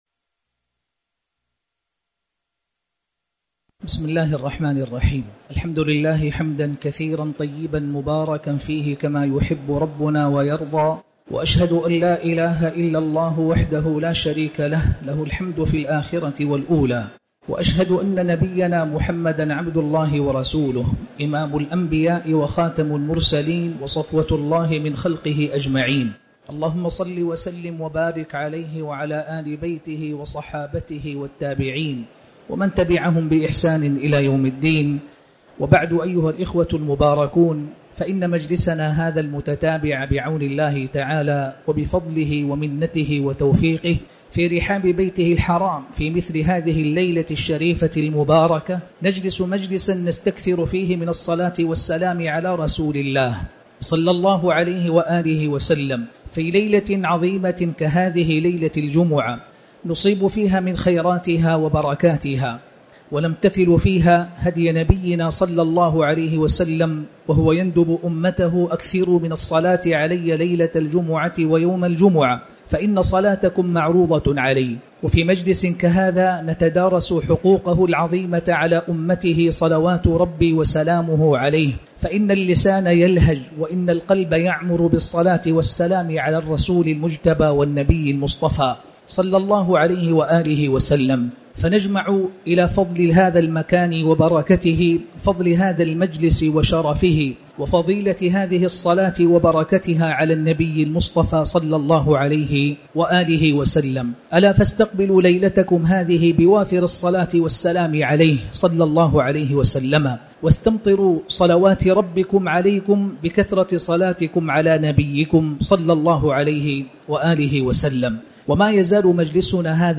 تاريخ النشر ٣٠ جمادى الآخرة ١٤٤٠ هـ المكان: المسجد الحرام الشيخ